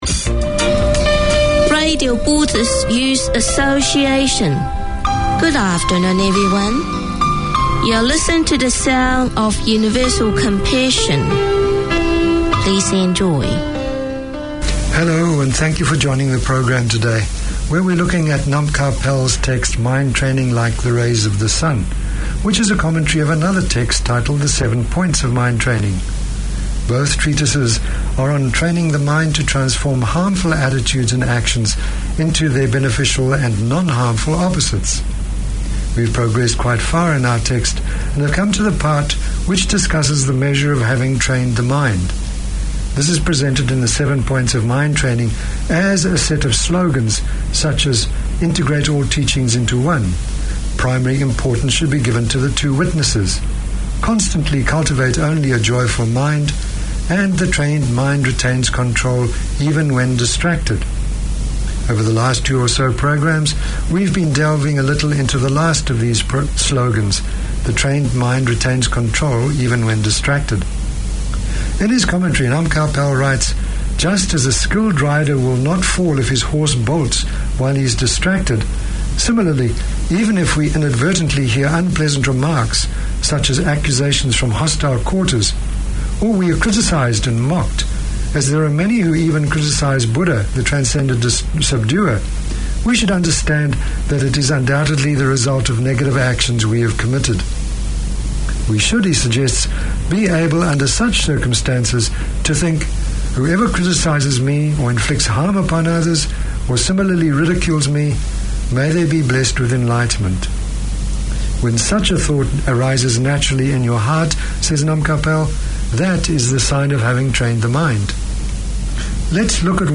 This half hour series features interviews and discussions on matters of interest to women in general and migrant women in particular. Women working in the community talk to women with shared experiences, to people who can help, to female achievers.